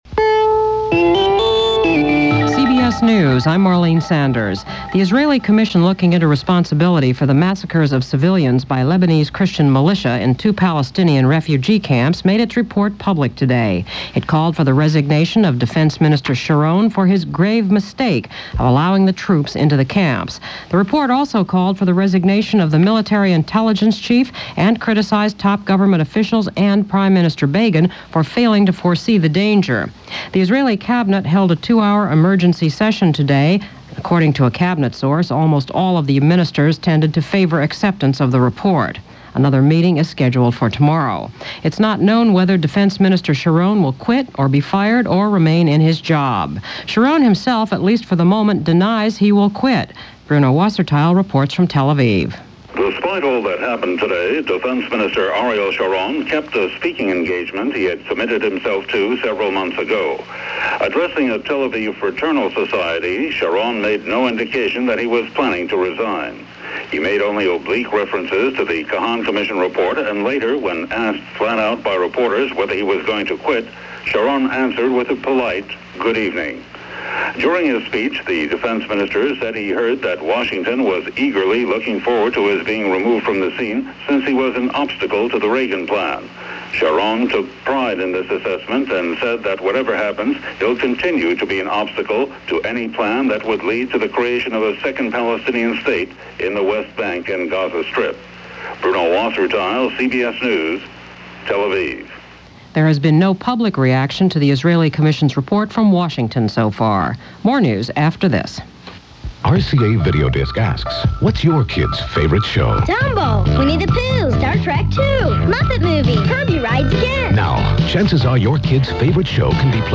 And so it went – for February 8, 1983 as reported via CBS Radio Hourly News and CBS News Business Update.